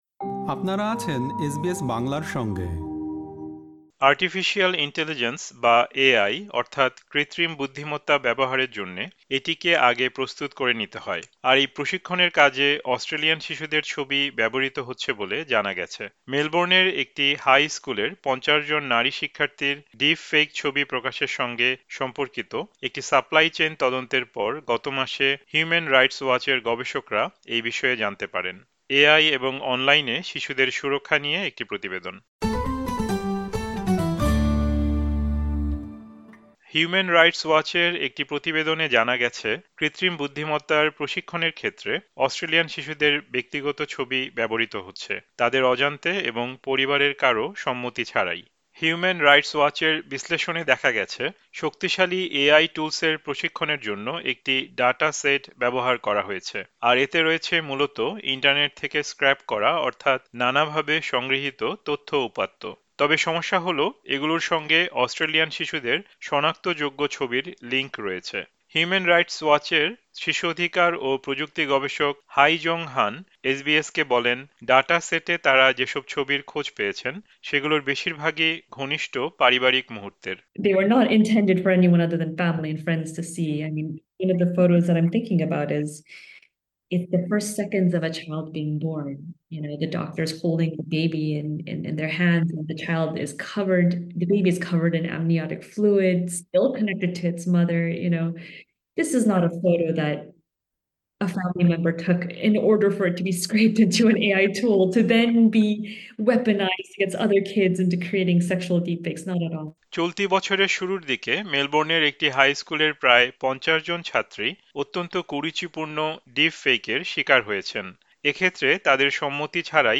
এ-আই এবং অনলাইনে শিশুদের সুরক্ষা নিয়ে একটি প্রতিবেদন।